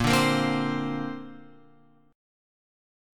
A#add9 chord {6 5 8 5 x 8} chord